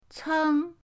ceng1.mp3